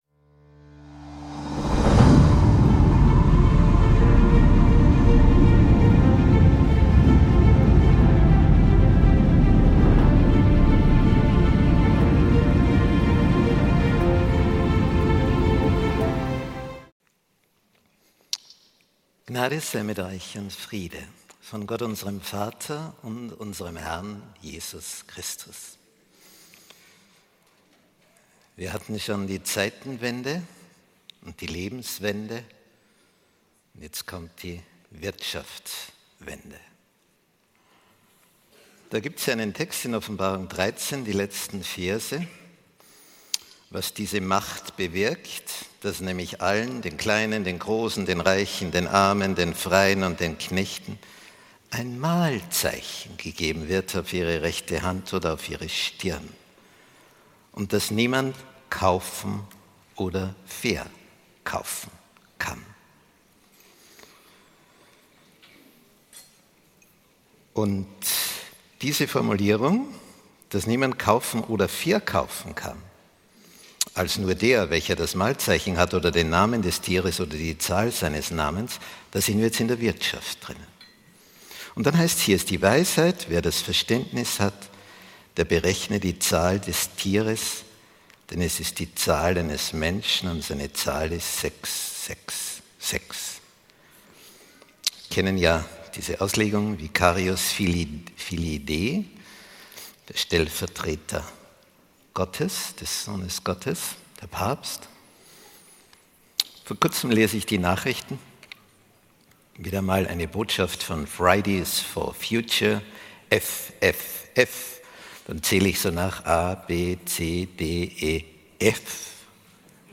Eine spannende Reise durch die Geschichte zur aktuellen Wirtschaftswende. Im Zuge der globalen Entwicklungen zeigt der Vortrag, wie religiöse und wirtschaftliche Strukturen miteinander verwoben sind. Die Verbindungen zwischen biblischen Prophezeiungen und geschichtlichen Ereignissen machen nachdenklich.